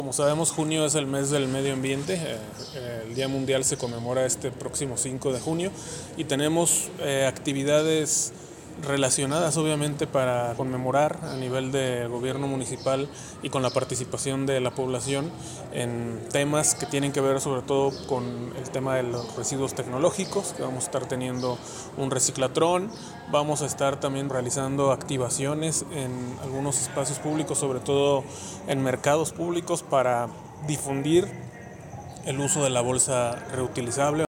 AudioBoletines
Gonzalo Guerrero Guerrero, director de Sustentabilidad